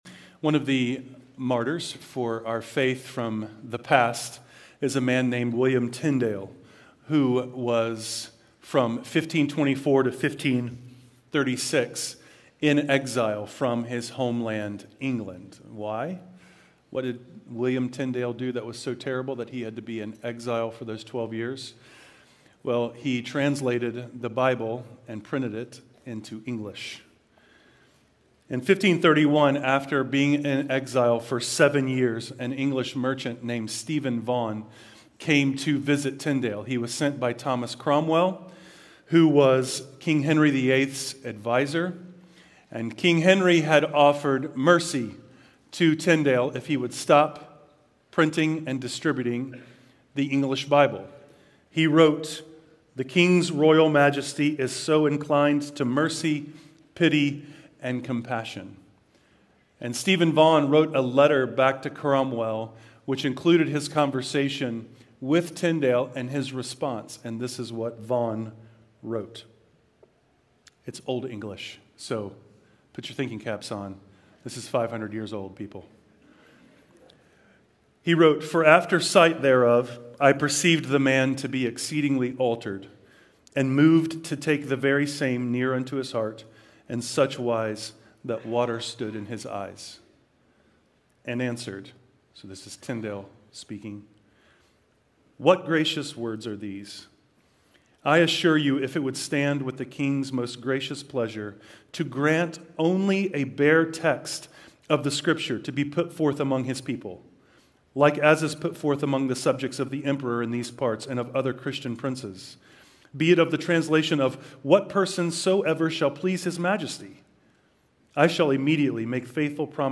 Sermons Archive - North Hills Church
Teaching from North Hills Church - Greenville, SC